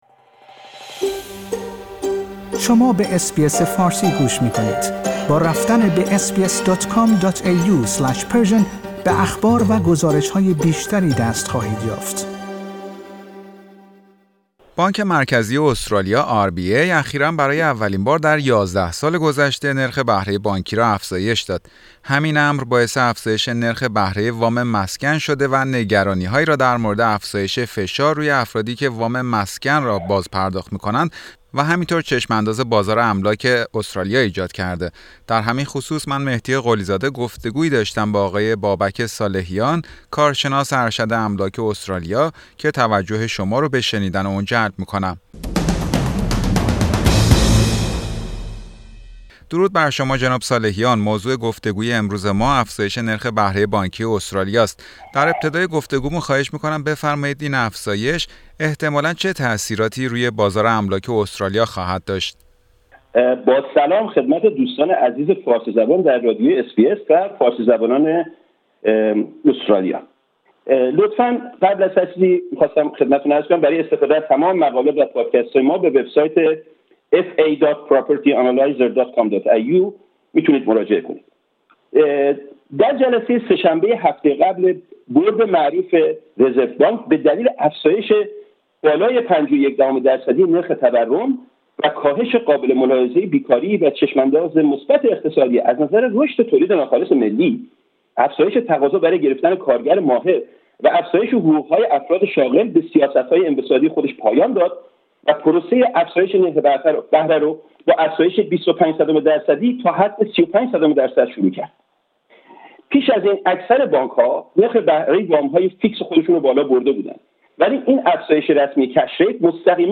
در همین خصوص برنامه فارسی رادیو اس بی اس گفتگویی داشته